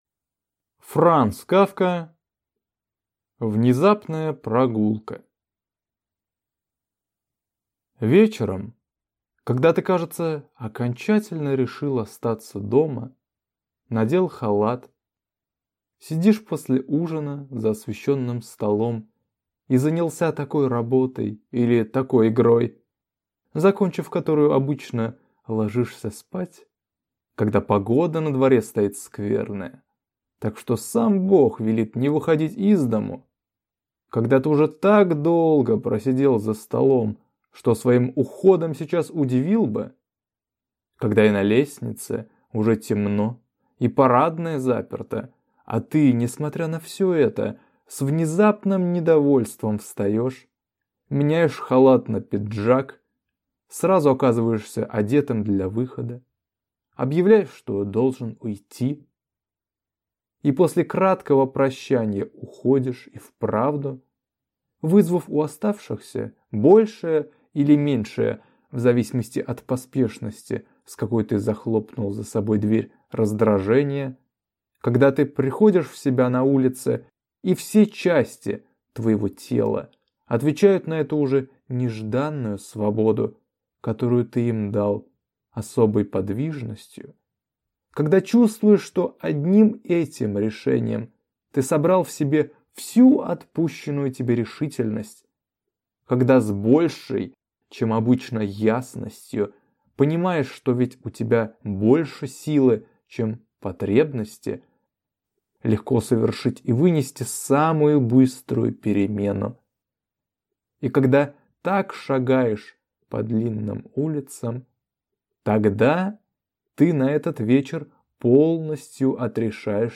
Аудиокнига Внезапная прогулка | Библиотека аудиокниг